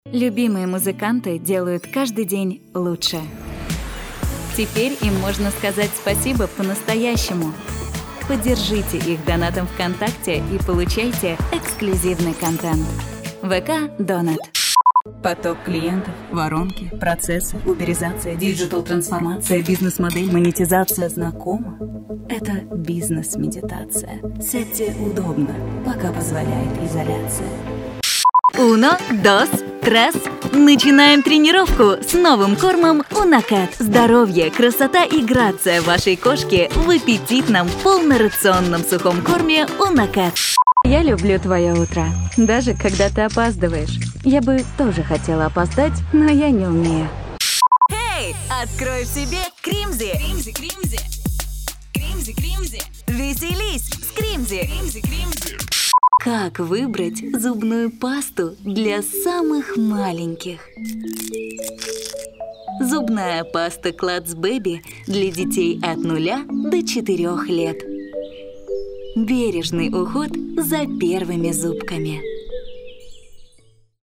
Commerciale, Jeune, Naturelle, Amicale, Corporative
Commercial
- native russian speaker without region accent
- professional-level voice-over, with professional equipment, provide guaranteed sound quality